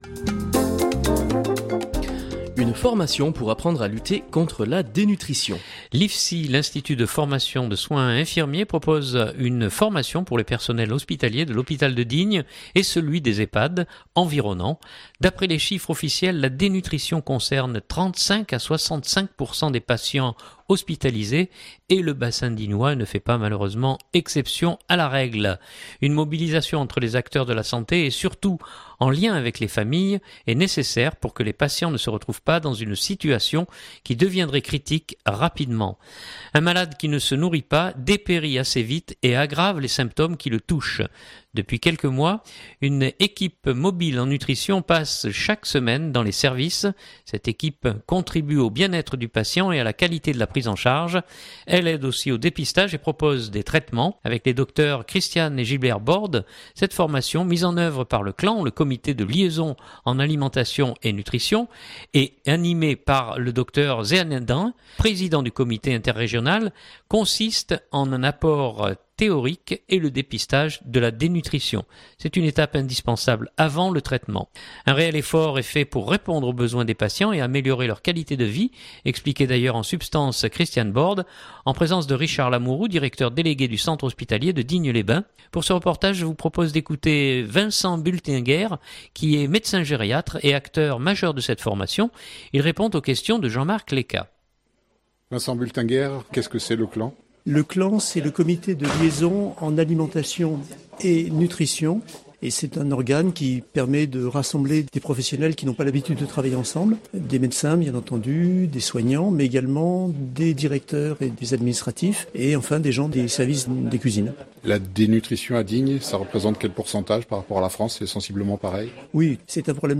Pour ce reportage